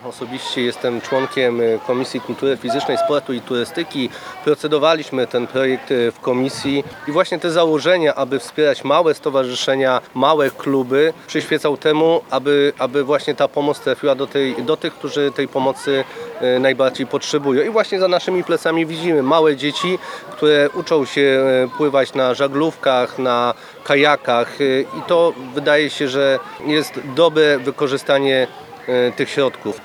W spotkaniu wziął udział także Wojciech Kossakowski, poseł Prawa i Sprawiedliwości, który zasiada w sejmowej komisji sportu.